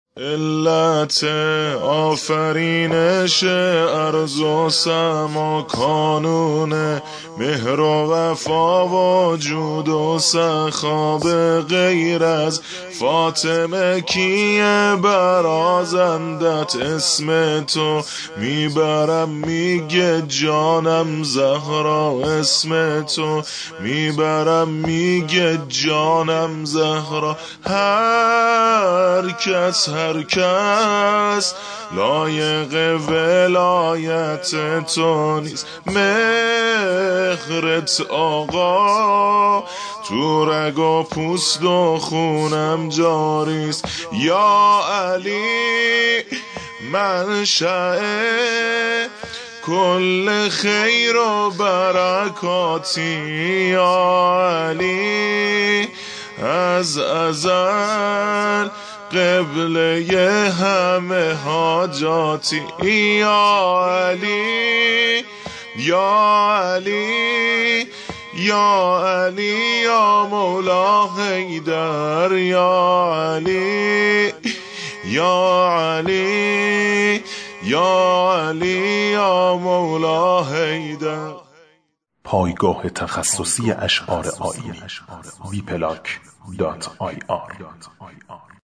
شور ، واحد